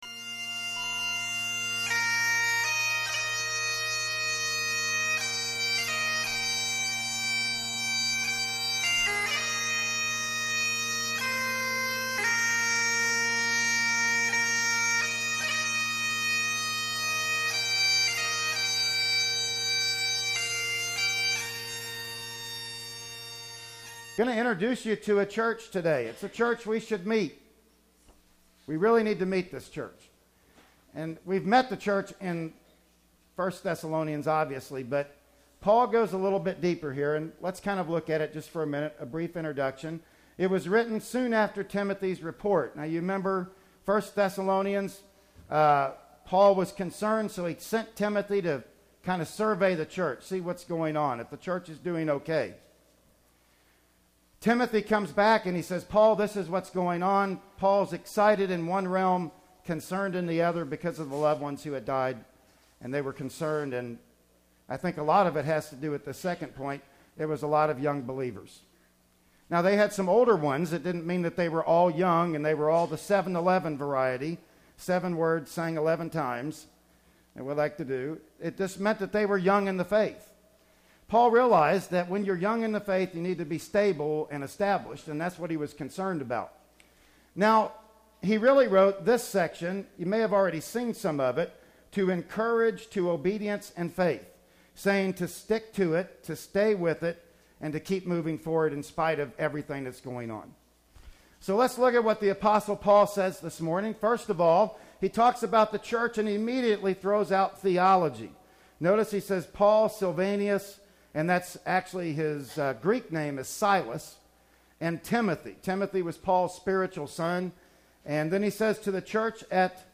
"2 Thessalonians 1:1-4" Service Type: Sunday Morning Worship Service Bible Text